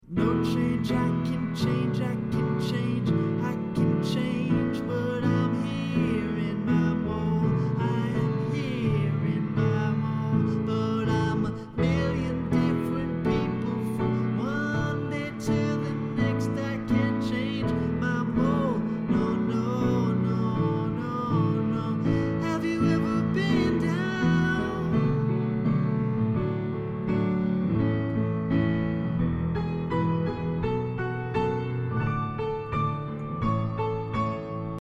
string riff drops